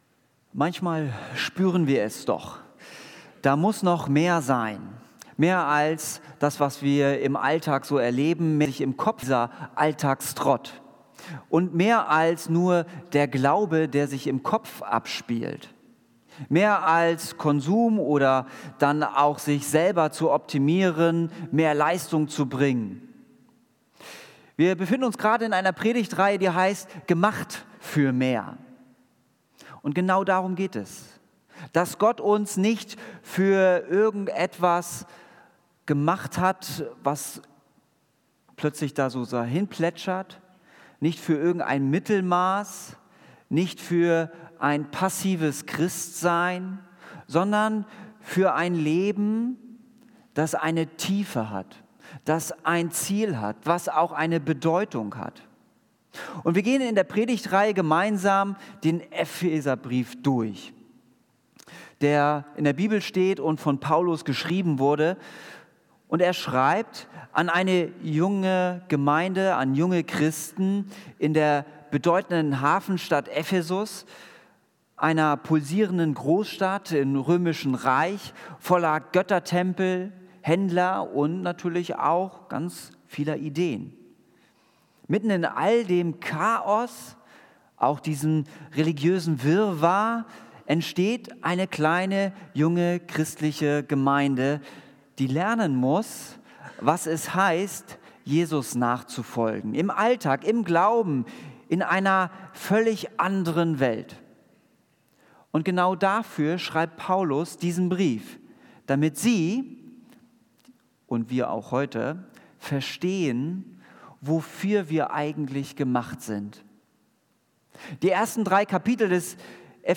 Predigt Mehr bewegen